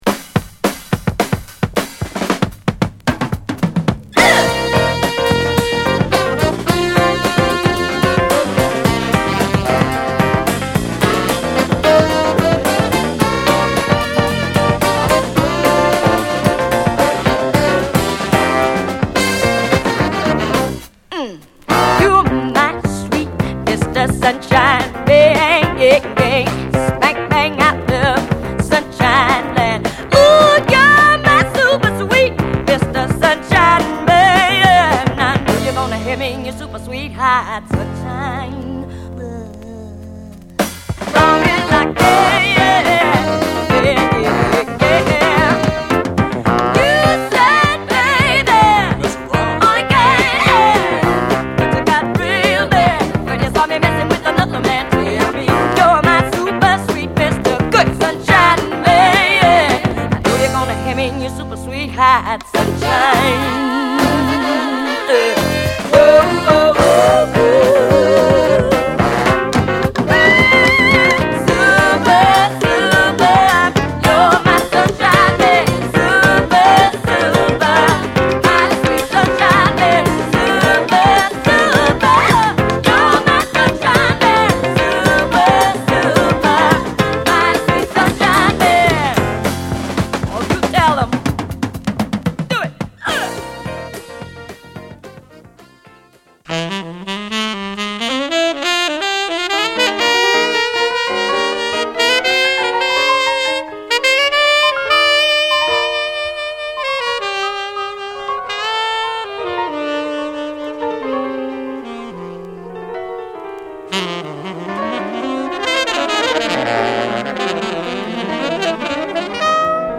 パワフルなヴォーカルを聞かせてくれます！